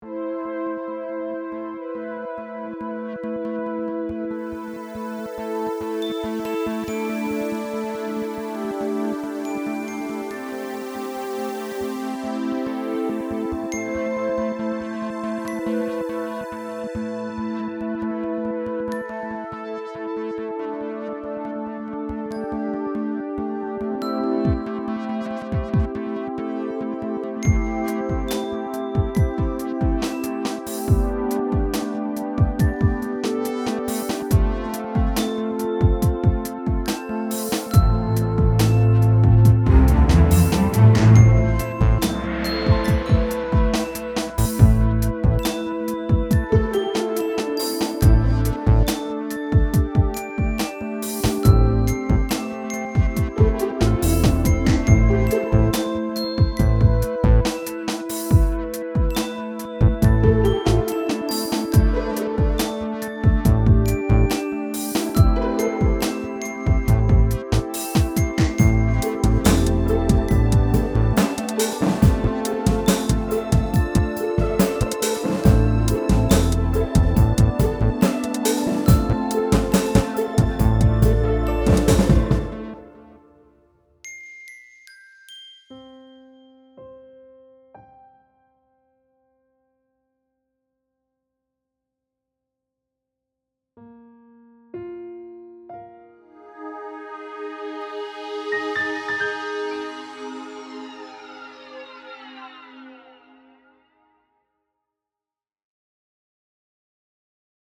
Genre: Soundtrack.